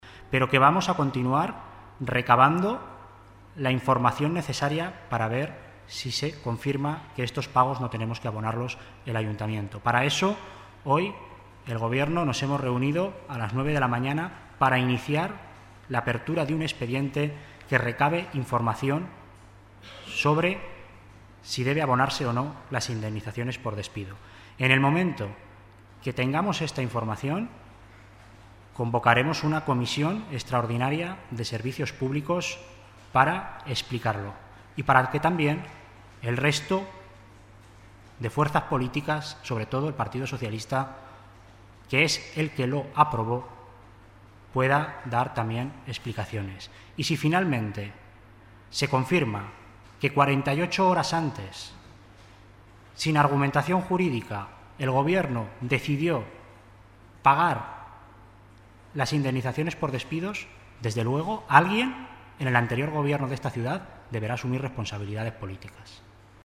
Así lo ha explicado en rueda de prensa el Consejero de Servicios Públicos y Personal, Alberto Cubero, quien ha calificado de "vergonzosa" esta situación y ha dicho que "este Gobierno no admite el pago de despidos con dinero público, en una decisión adoptada, además, en el último minuto de la anterior legislatura".